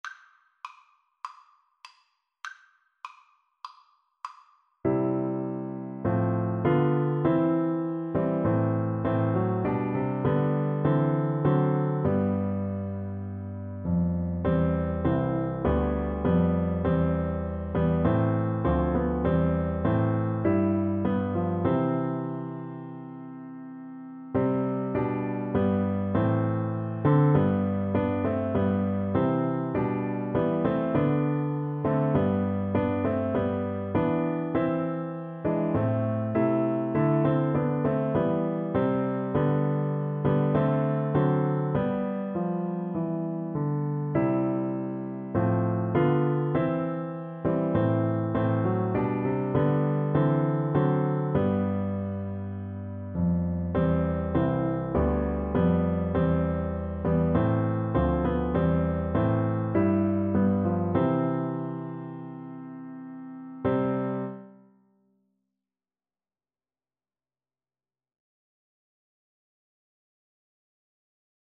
Play (or use space bar on your keyboard) Pause Music Playalong - Piano Accompaniment Playalong Band Accompaniment not yet available reset tempo print settings full screen
4/4 (View more 4/4 Music)
C major (Sounding Pitch) (View more C major Music for Viola )
Christian (View more Christian Viola Music)